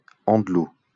Andlau (French pronunciation: [ɑ̃dlo]
or [andlau];[3] Alsatian: Àndlöi) is a commune in the Bas-Rhin department in Alsace, Grand Est region of northeastern France.